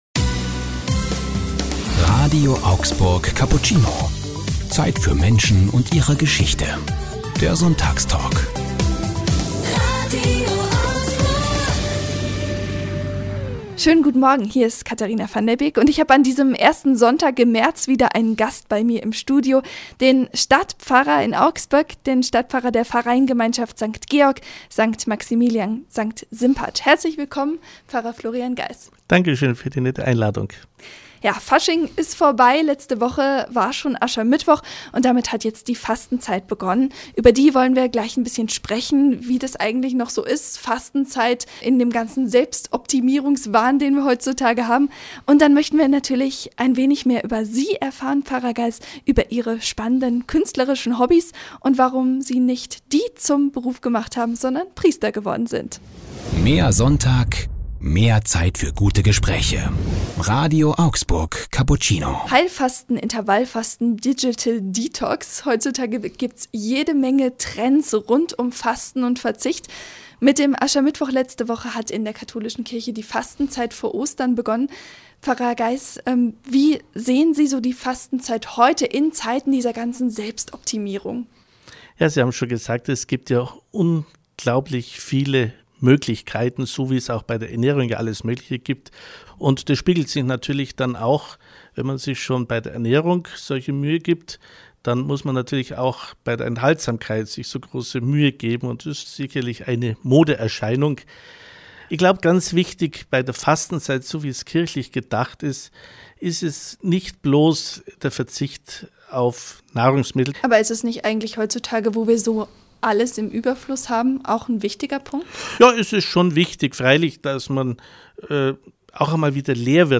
Sie haben den Sonntagstalk verpasst?